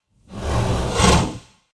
sounds_horse_snort_01.ogg